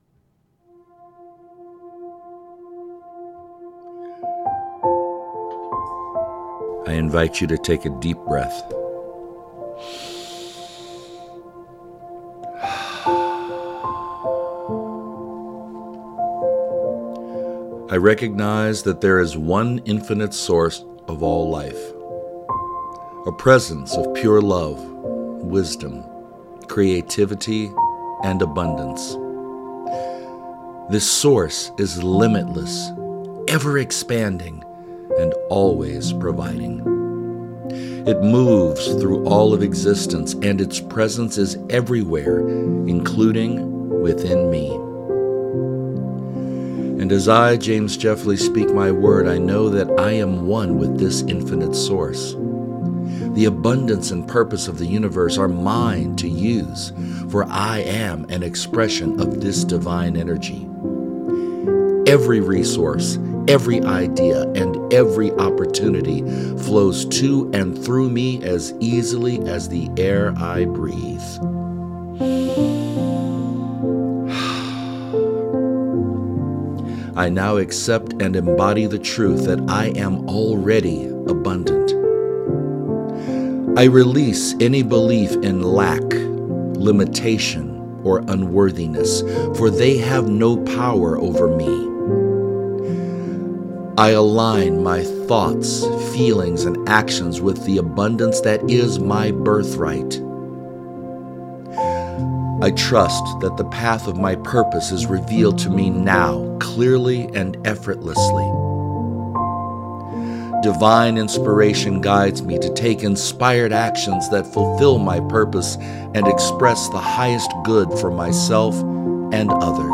A spoken spiritual treatment to help you align with prosperity and meaning.